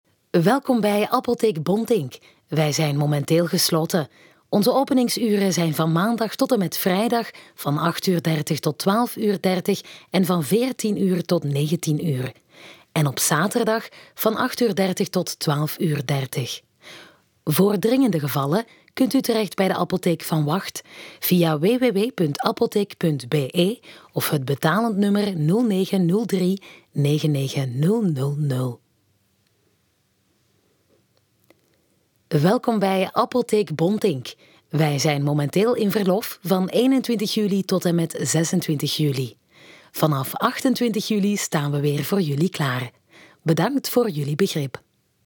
Voz
Profundo, Joven, Travieso, Versátil, Cálida
Telefonía